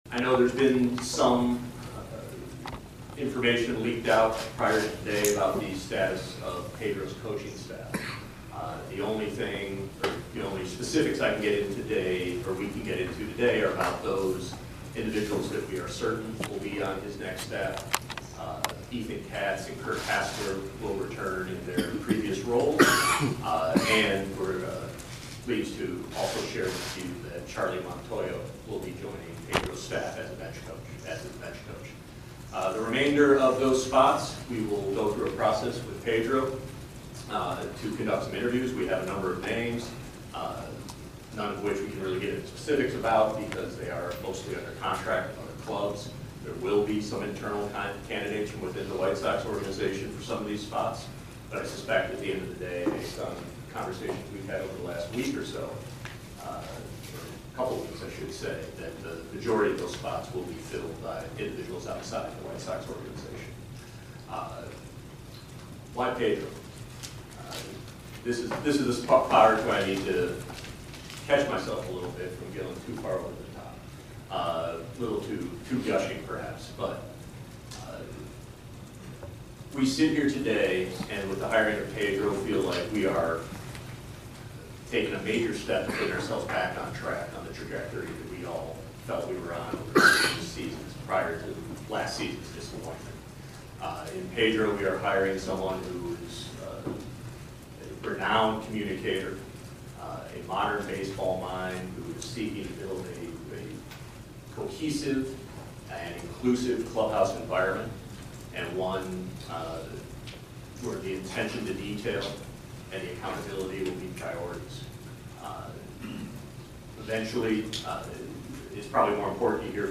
Classic MLB interviews